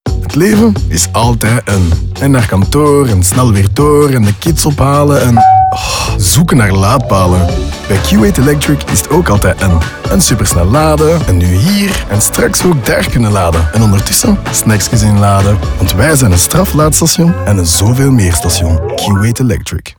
De muziek en het sounddesign maken deel uit van een nieuwe sonic identity, gecreëerd door Sonhouse.
Q8 Electric Radio 20_ NL.wav